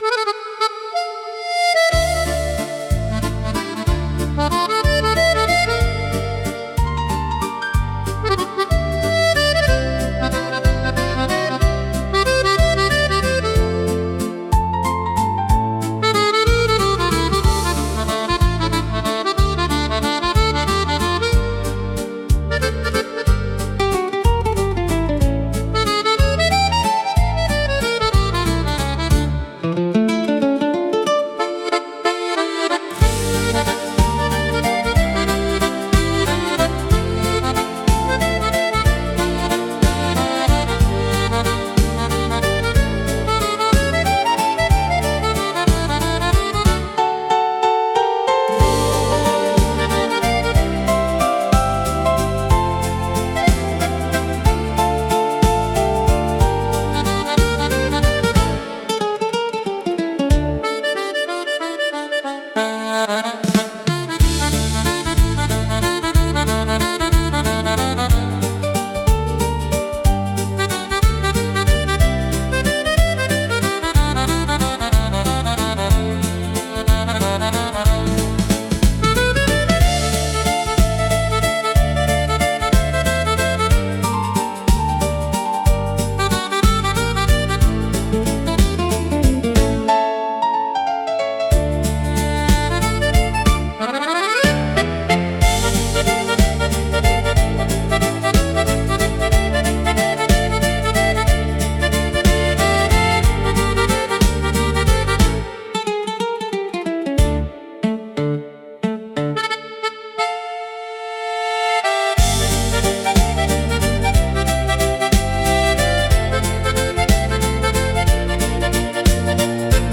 минус
• Жанр: Детские песни
летний вальс